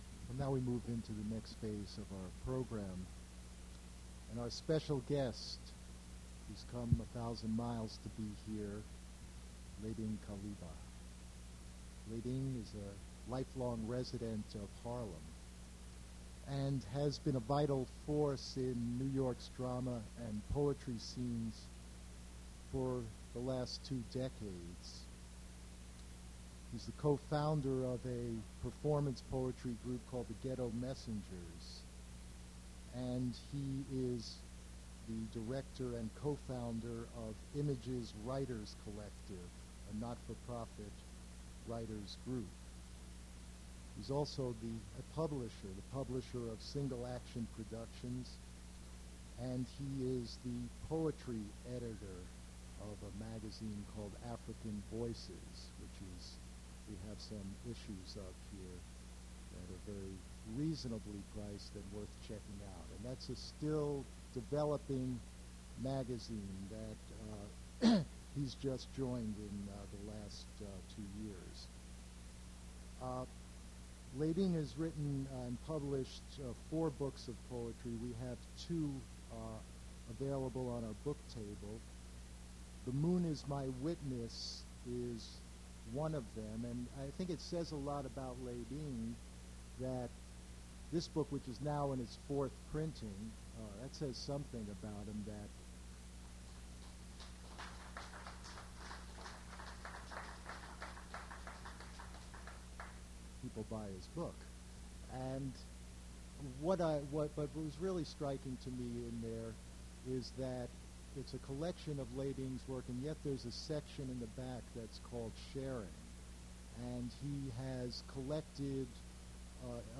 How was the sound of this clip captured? generated from original audio cassette low quality recording